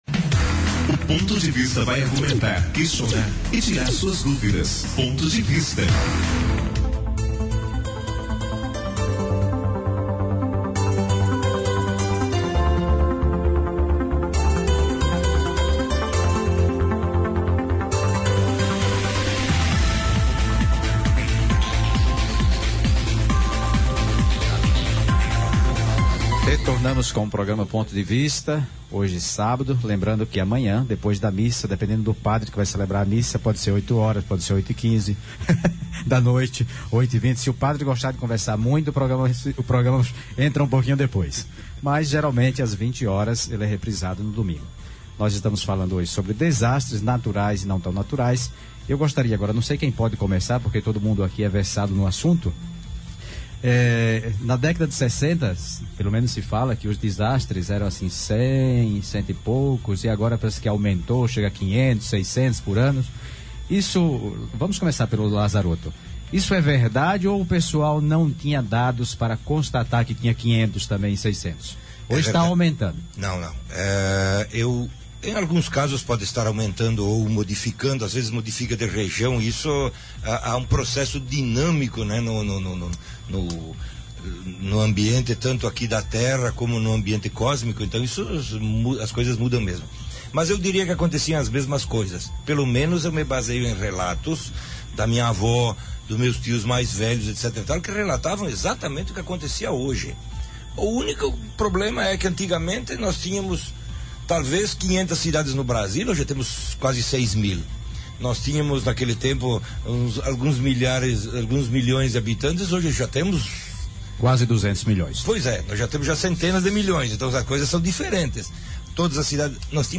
convidados discutem neste programa as agressões ao meio ambiente e suas consequências.